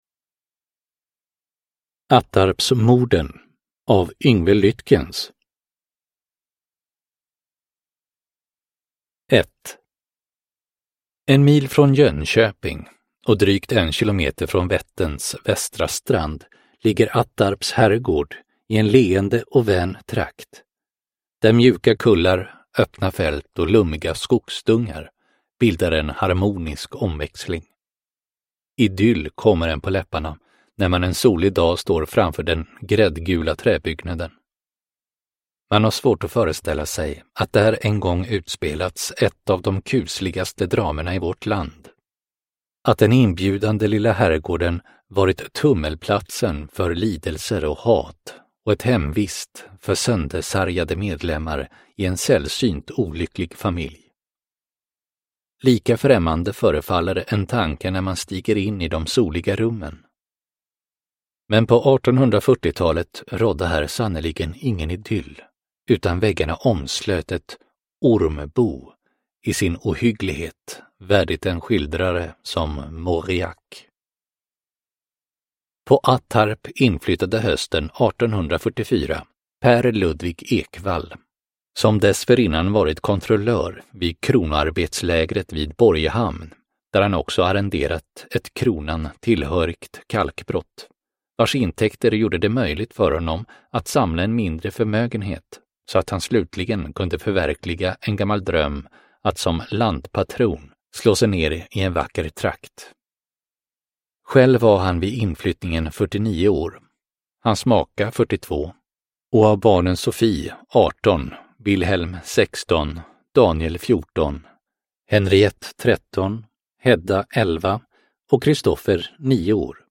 Attarpsmorden : Historiska mord del 6 – Ljudbok – Laddas ner